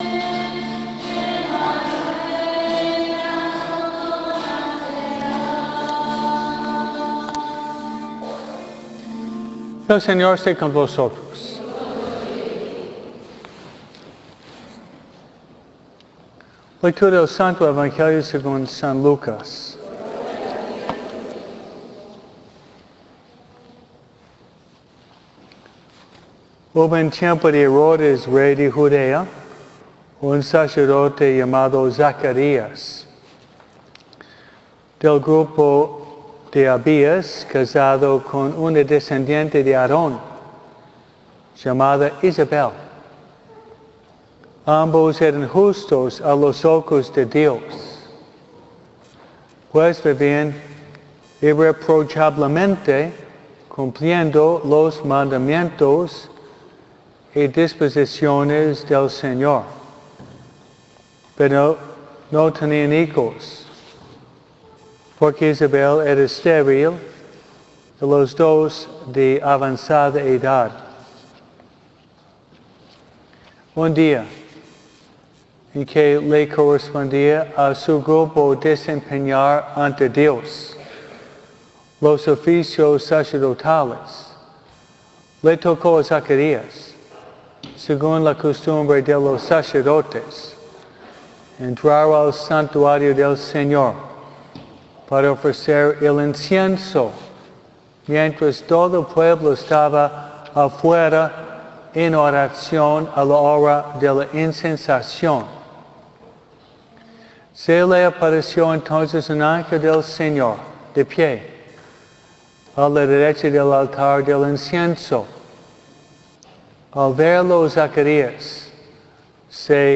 283S-MISA-DE-NINOS.mp3